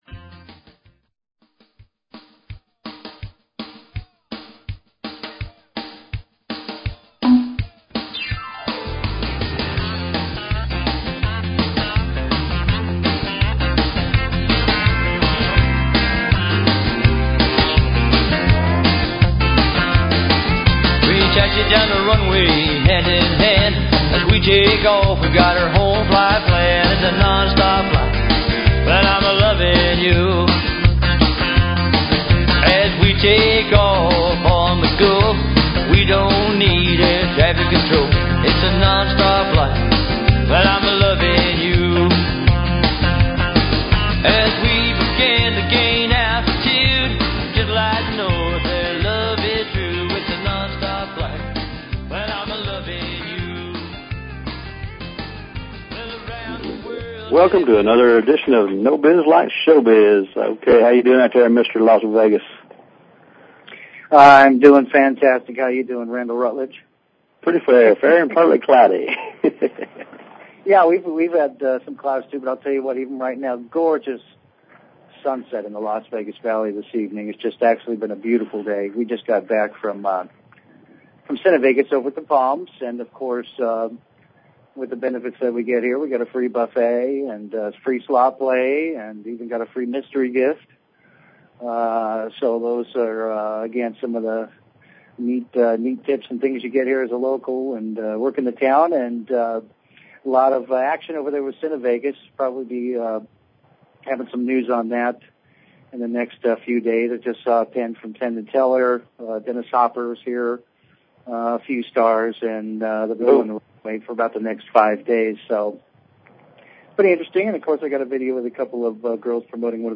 Talk Show Episode, Audio Podcast, No_Biz_Like_Showbiz and Courtesy of BBS Radio on , show guests , about , categorized as